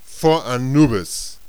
warrior_ack4.wav